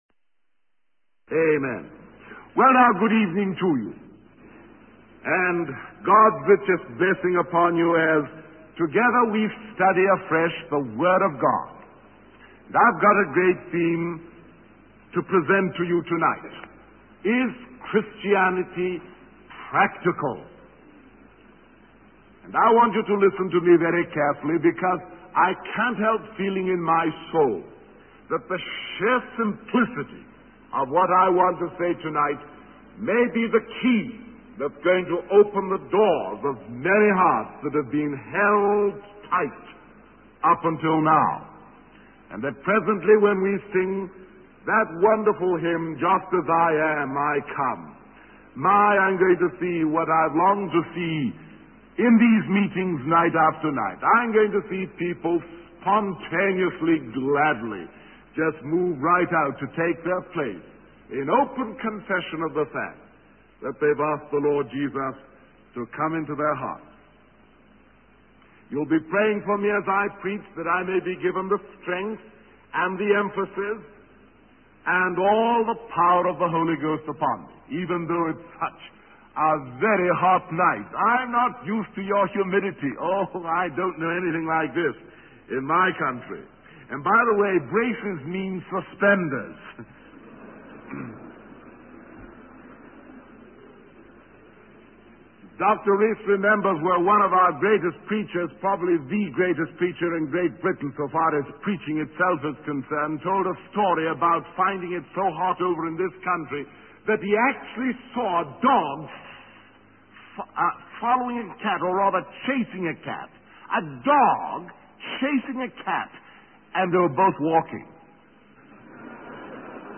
In this sermon, the speaker emphasizes the power of the spoken word of God. He explains that just as the energy of God's word created and sustains the physical world, it also has the power to bring spiritual life to individuals.